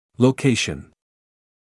[ləu’keɪʃn][лоу’кейшн]расположение, локация